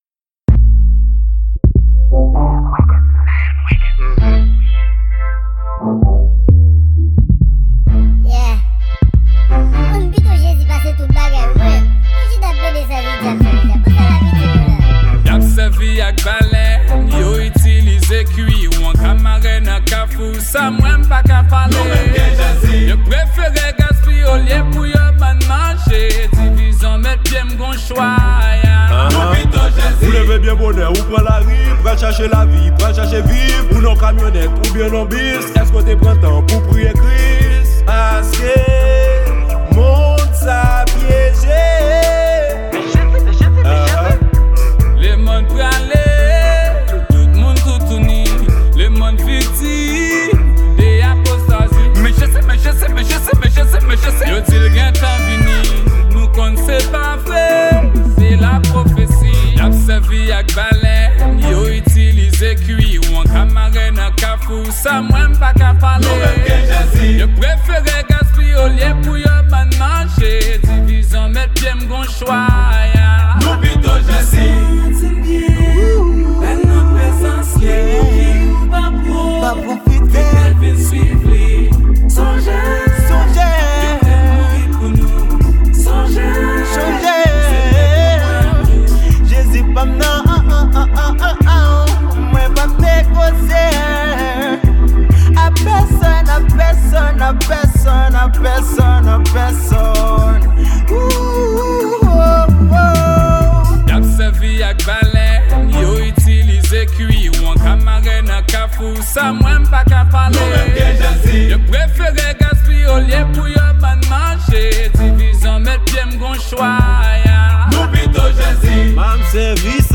Genre: GOSPEL .